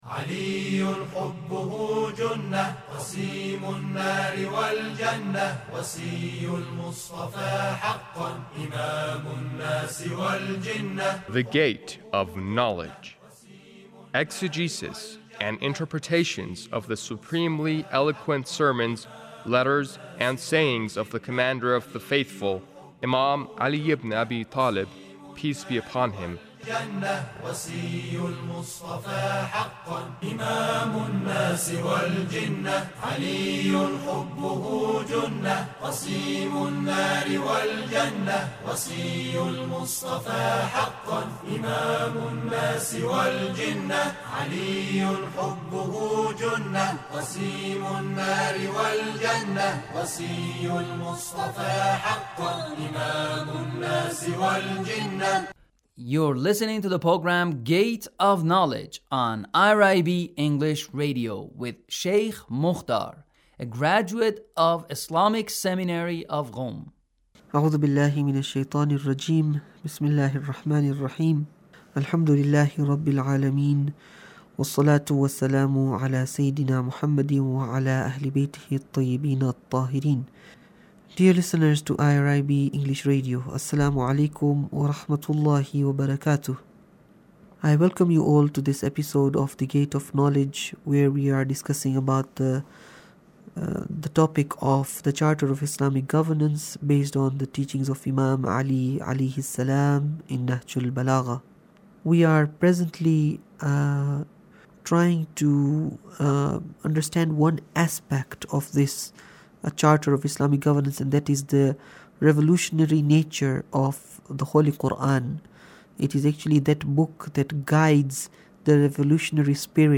Sermon 1 -